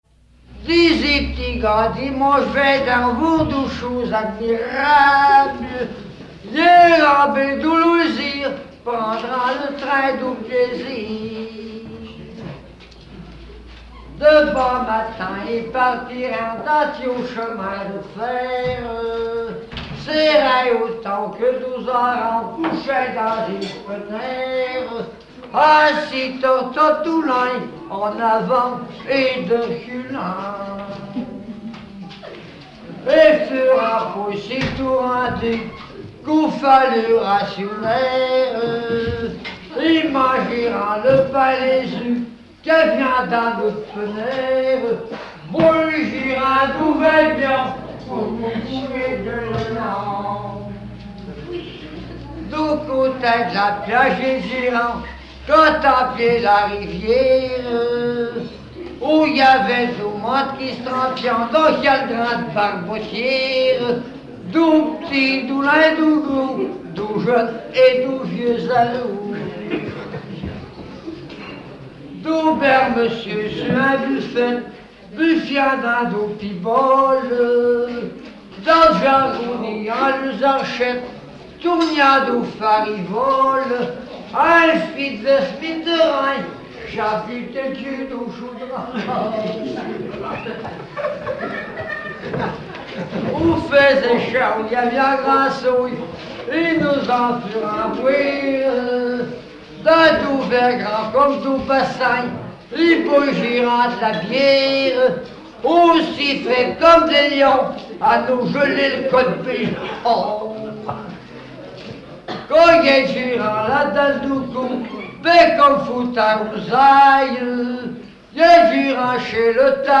Repas de midi
Catégorie Pièce musicale inédite